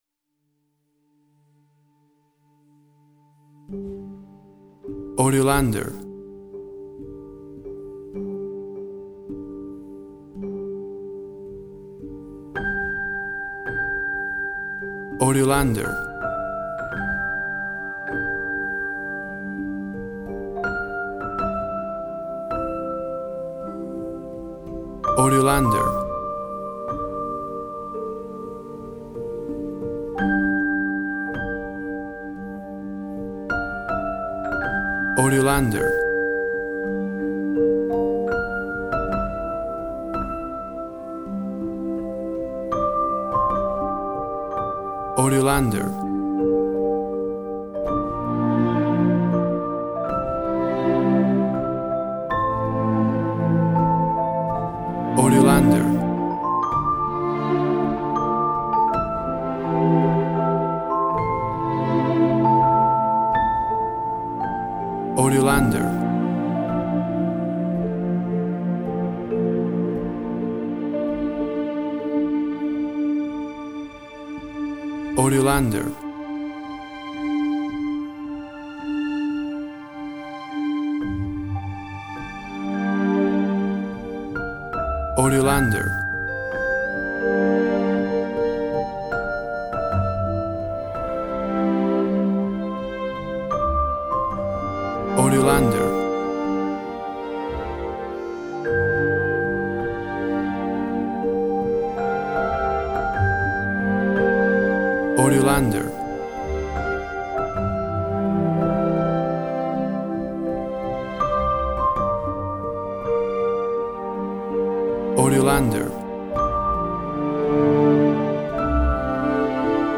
A subtle caress.
Tempo (BPM) 160